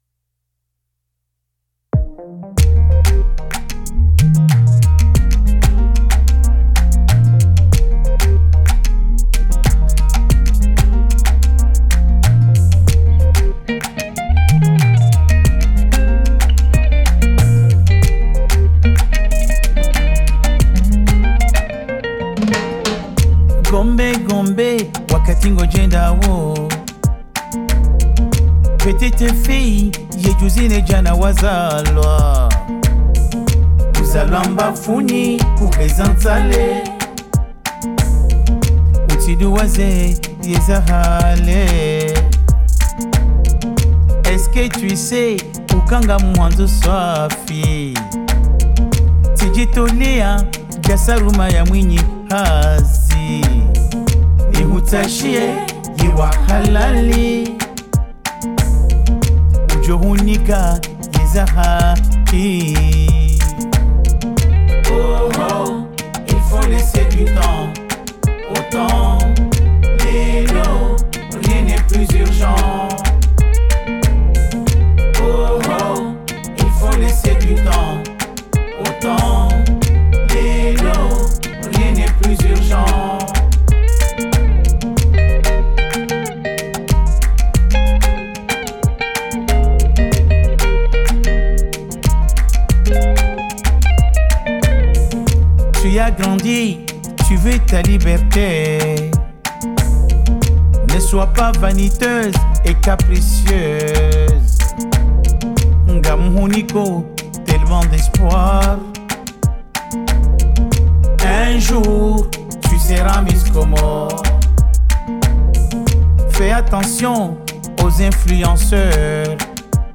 dans sa résonance à l’acoustique